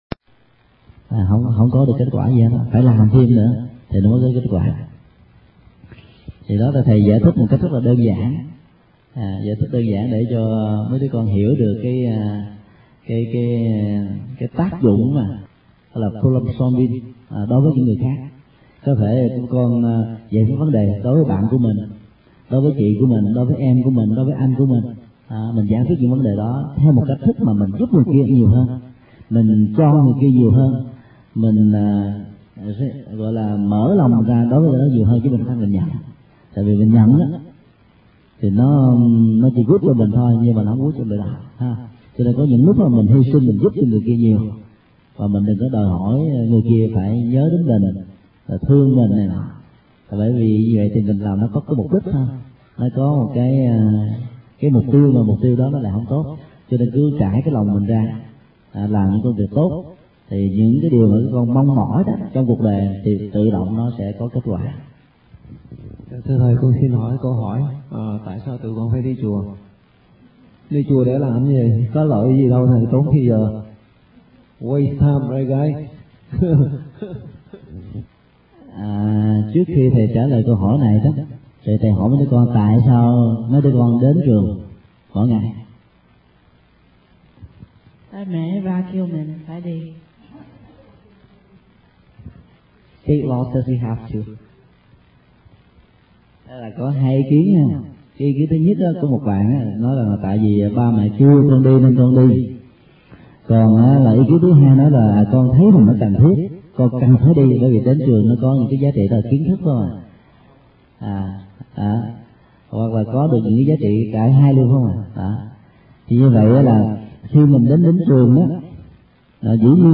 Sinh hoạt thiếu nhi Dallas - Mp3 Thầy Thích Nhật Từ Thuyết Giảng
Tải mp3 Thuyết Giảng Sinh hoạt thiếu nhi Dallas - Thầy Thích Nhật Từ giảng ngày 2 tháng 10 năm 2004